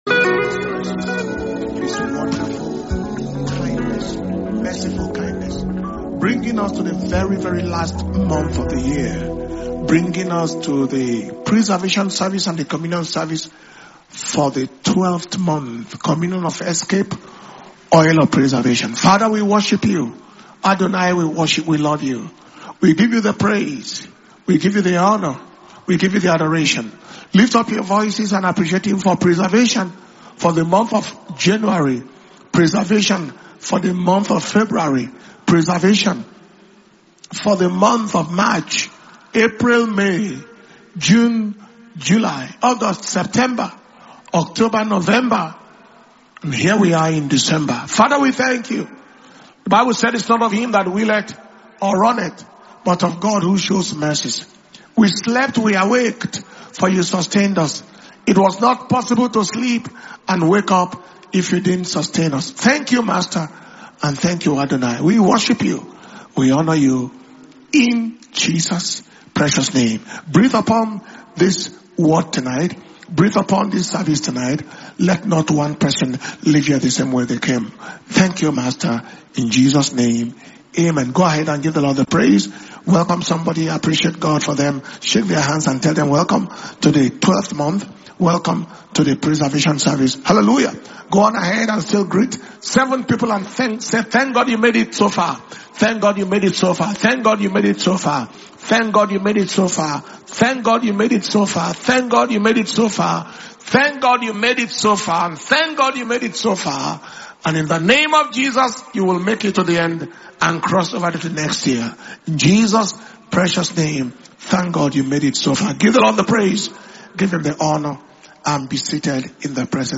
December 2025 Preservation And Power Communion Service